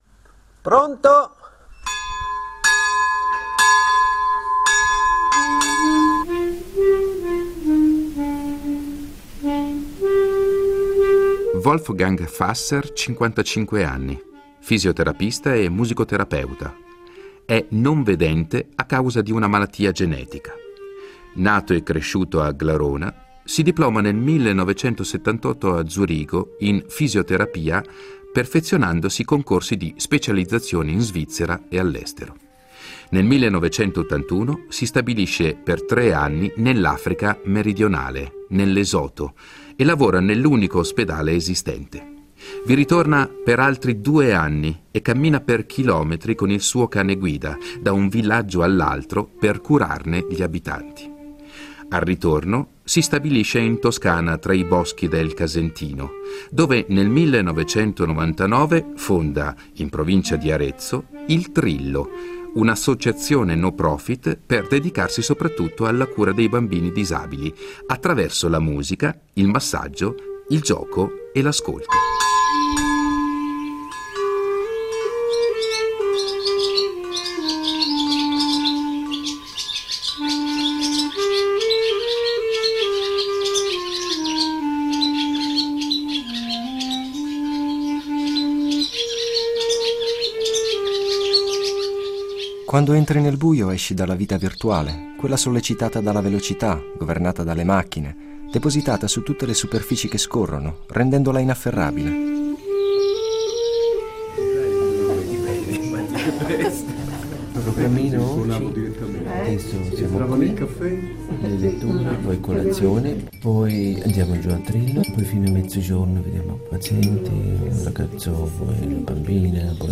Un incontro di suoni e parole per raccontare un uomo che ha fatto del silenzio senza luce dei suoi occhi, della sua cecità, un’occasione per trovare un profondo equilibrio, una via di accesso al rapporto con sé stesso e con gli altri.